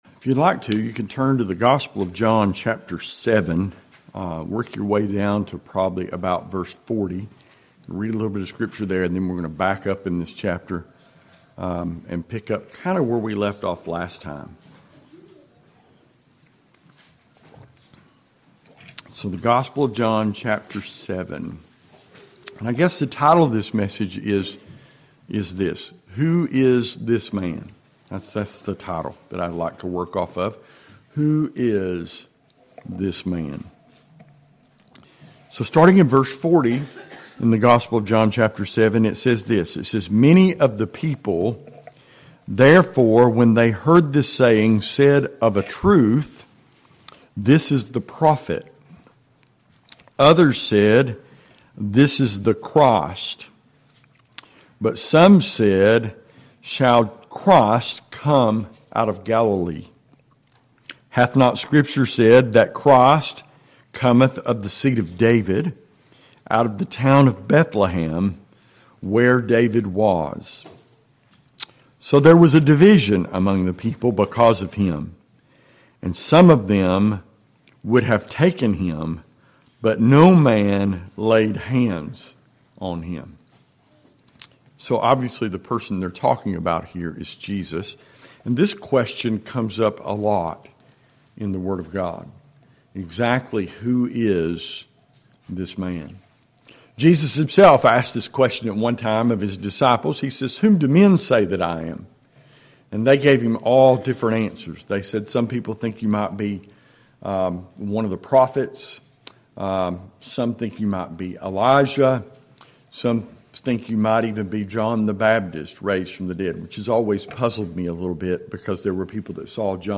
– Dallas Primitive Baptist Church